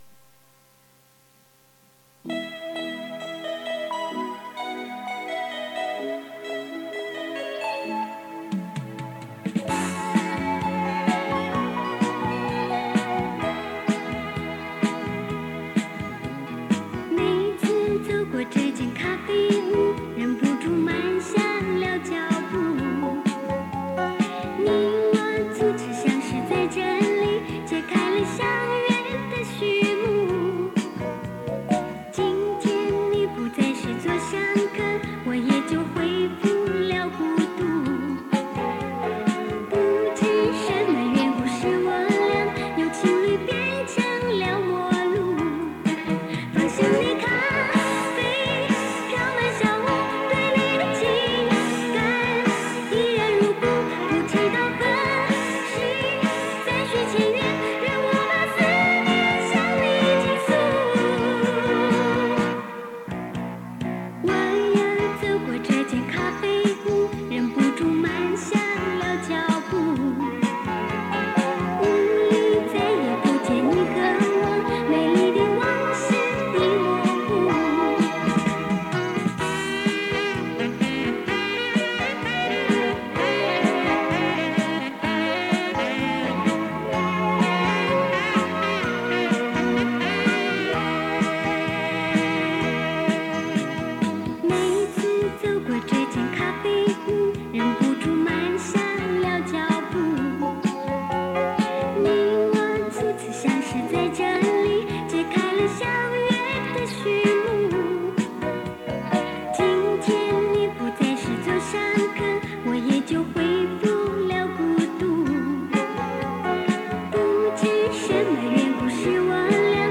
磁带数字化：2022-06-05